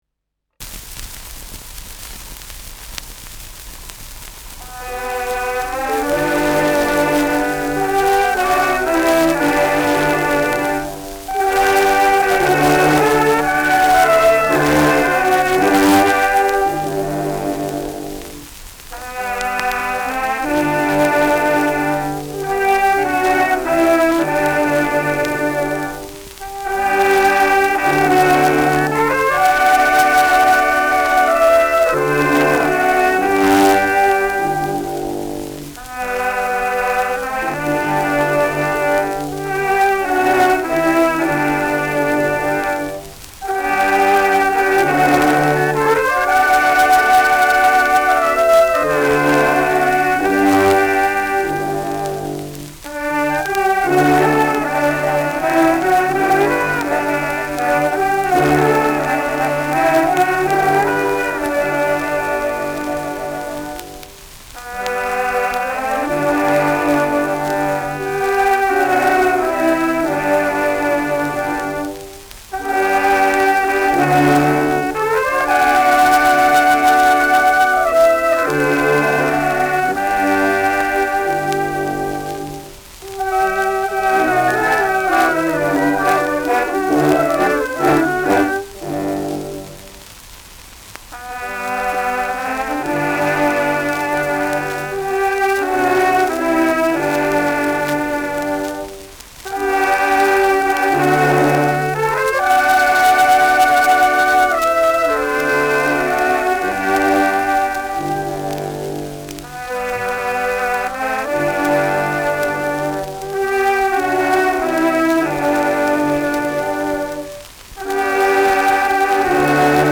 Schellackplatte
abgespielt : leiert : präsentes Rauschen : gelegentliches Knistern : gelegentliches Nadelgeräusch
Stadtkapelle Fürth (Interpretation)
Mit Juchzer.